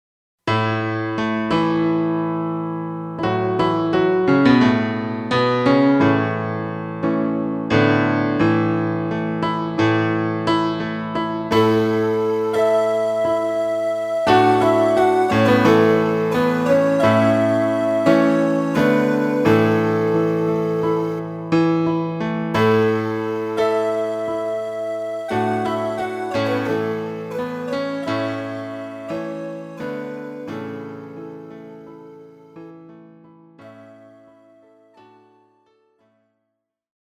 Žánr: Folk Rock
MP3 ukázka